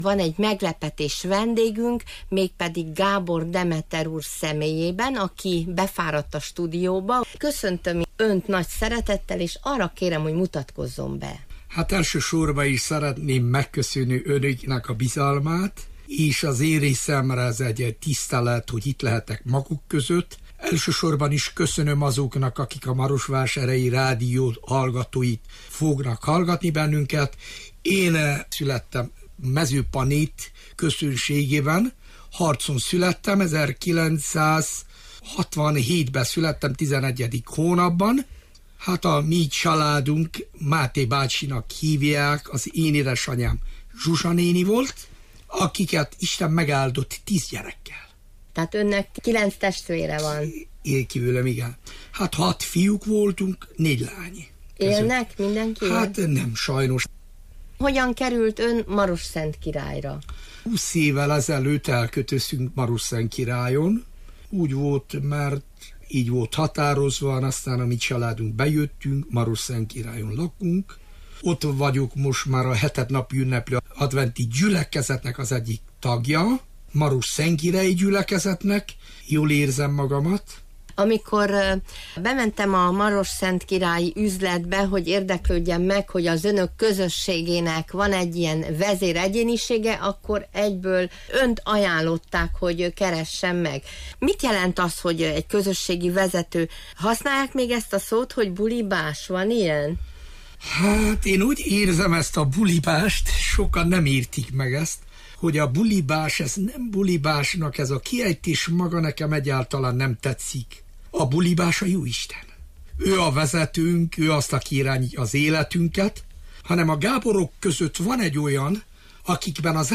Vállalta, hogy befárad a stúdióba, ott rögzítettük vele ezt a beszélgetést.